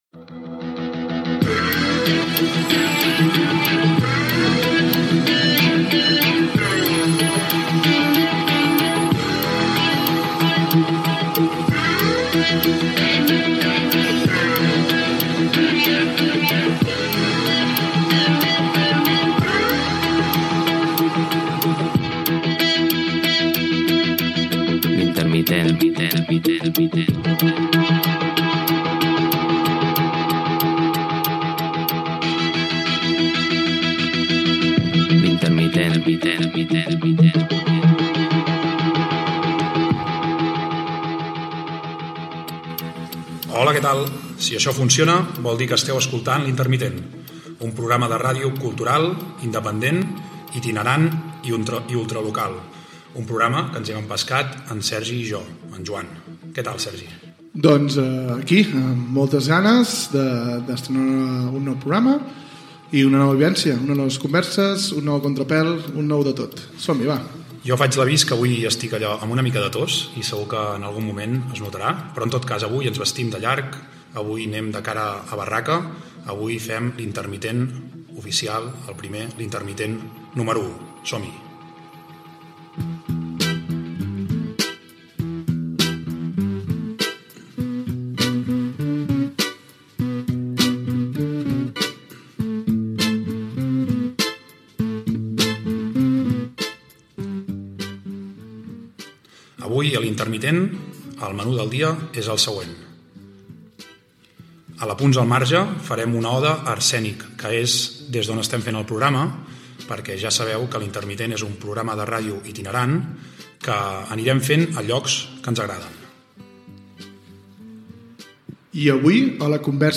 Careta, presentació del primer programa, sumari de continguts, "Apunts al marge" dedicada a l'escola d'arts escèniques Arsènic de la Fàbrica Roca Humbert de Granollers
Presentador/a